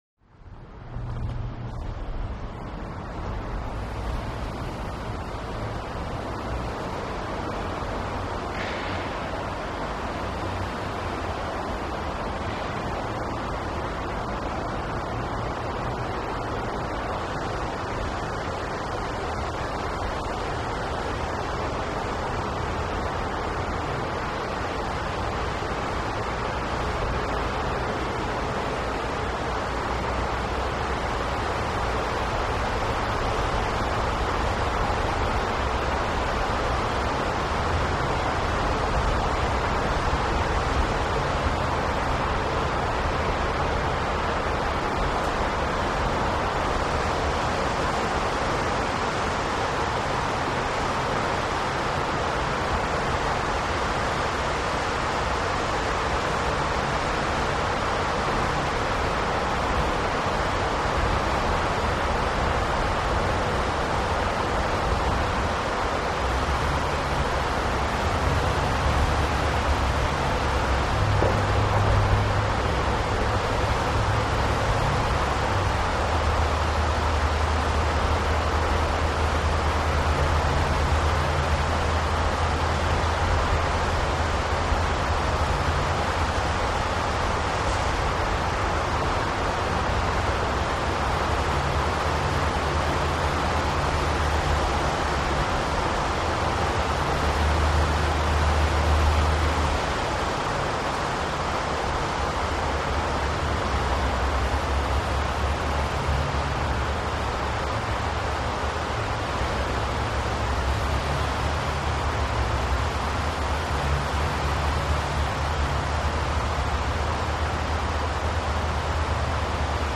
DrivingFastTunnelW CT051001
Driving Fast In Tunnel, Windows Open, Interior Car Point Of View. Light Traffic, Constant Airy Roar. Exit Tunnel At Tail. Could Play For Tunnel Ambience Or Car Exterior.